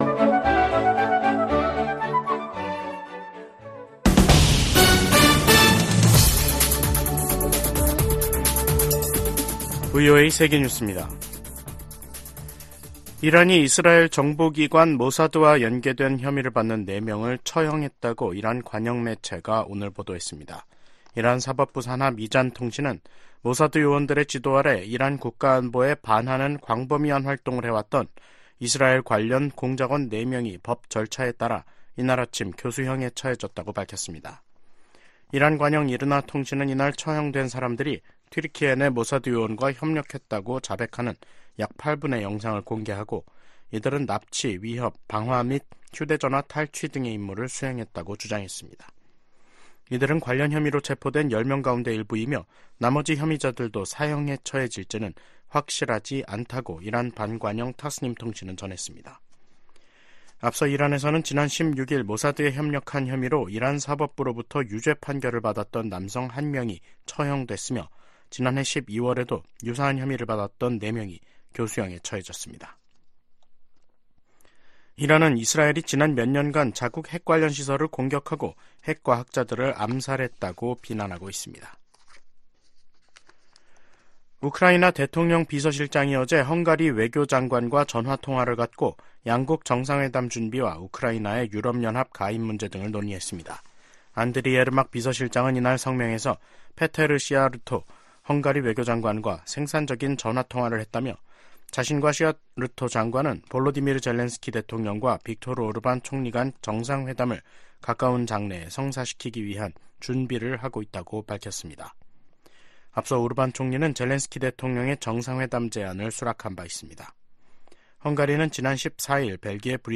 VOA 한국어 간판 뉴스 프로그램 '뉴스 투데이', 2023년 12월 29일 2부 방송입니다. 하마스가 북한의 유탄발사기 부품을 이용해 살상력이 큰 신무기를 만든 것으로 확인됐습니다. 미국 전문가들은 김정은 북한 국무위원장의 '전쟁준비 완성' 지시에, 핵 도발은 김씨 정권의 종말을 뜻한다고 경고했습니다. 미국 내 일각에선 북한 비핵화 대신 핵무기 감축 협상이 현실적인 방안이라는 주장이 나오고 있습니다.